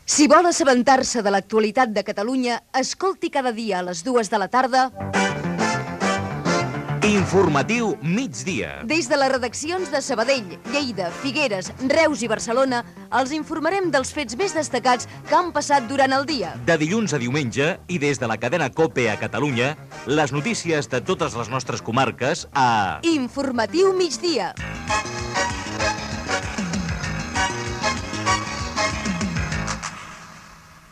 Promoció del programa amb els noms de les emissores que hi participen